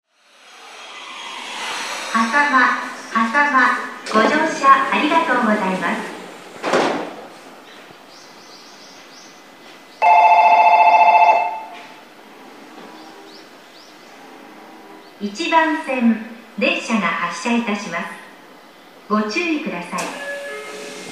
駅放送
到着発車 --